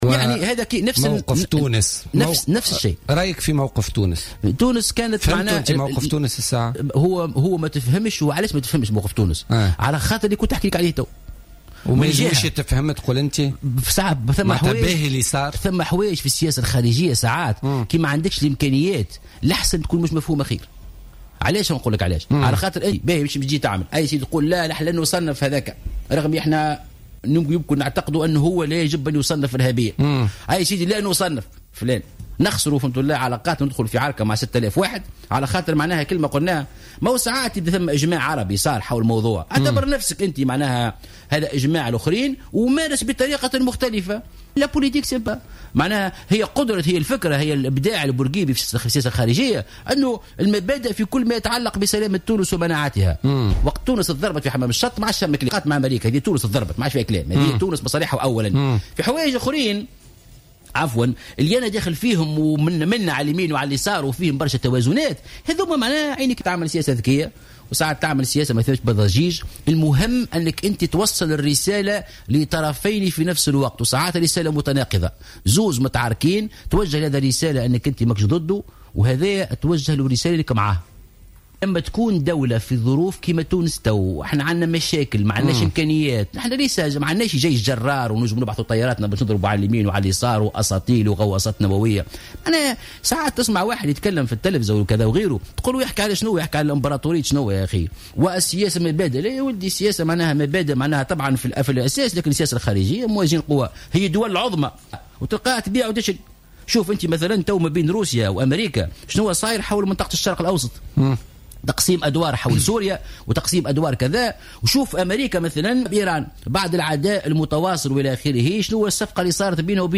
أعتبر محسن مرزوق المنسق العام لحركة مشروع تونس وضيف برنامج بوليتيكا لليوم الأربعاء 23 مارس 2016، أن تونس، وباختيارها لعدم الوضوح، قد اتخذت الموقف الذي يجب اتخاذه فيما يتعلق بتصنيف جامعة الدول العربية لحزب الله اللبناني "منظمة إرهابية".